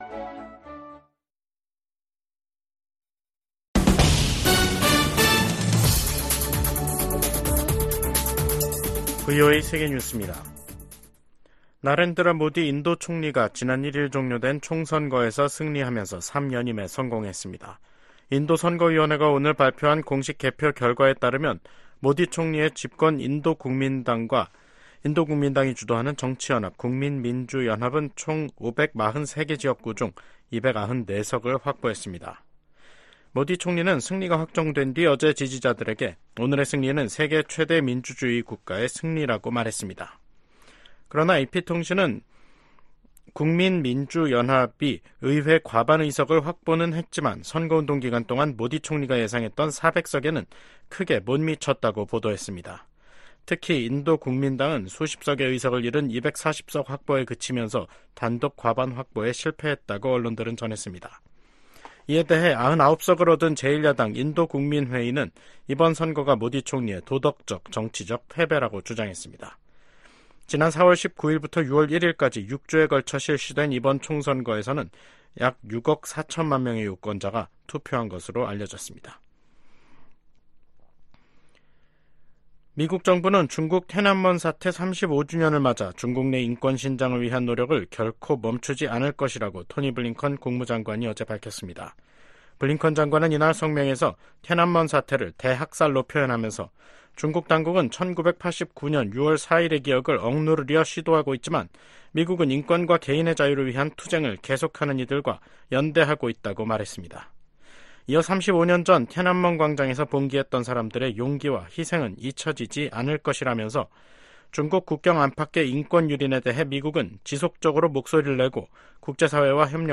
VOA 한국어 간판 뉴스 프로그램 '뉴스 투데이', 2024년 6월 5일 2부 방송입니다. 미국, 한국, 일본이 국제원자력기구 IAEA 정기 이사회에서 북한과 러시아의 군사 협력 확대를 비판하며 즉각 중단할 것을 한목소리로 촉구했습니다. 백악관이 북한 정권의 대남 오물풍선 살포 등 도발과 관련해 큰 우려를 가지고 주시하고 있다며 평양이 불필요한 행동을 계속하고 있다고 비판했습니다.